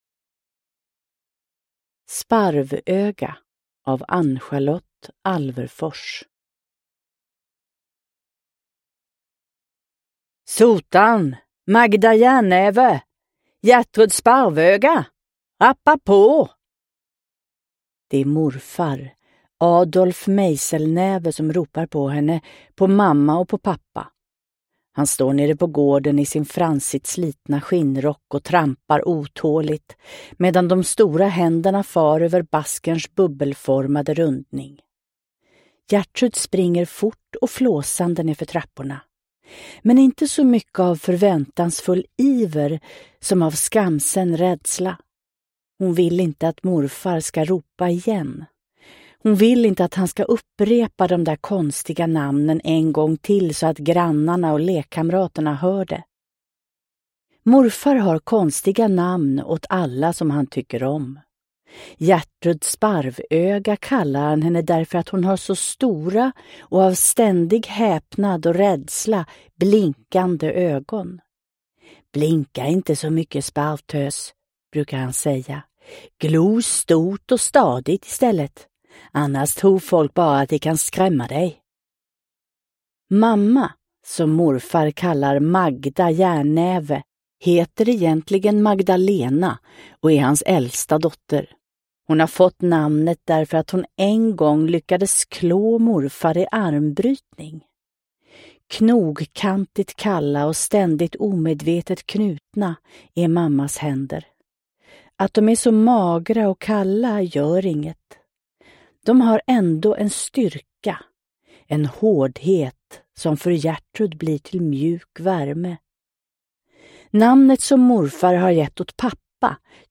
Sparvöga – Ljudbok – Laddas ner